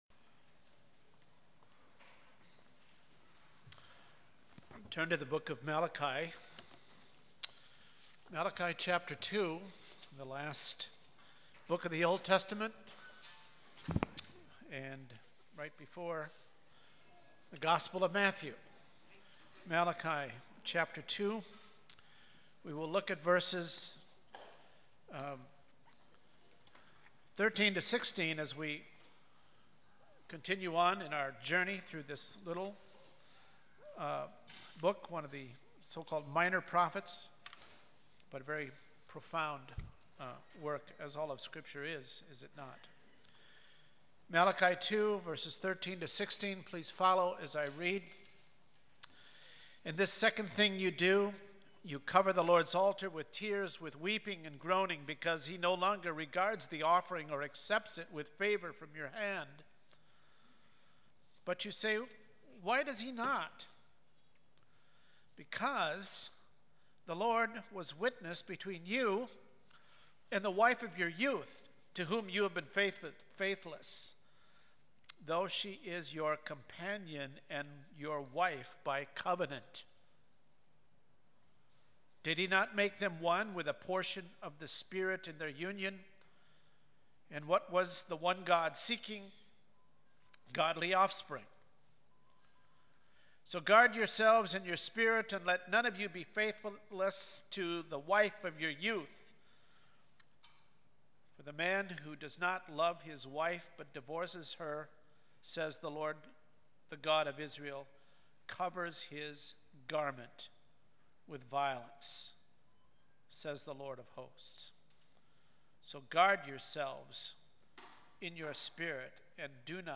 Passage: Malachi 2:13-16 Service Type: Sunday Morning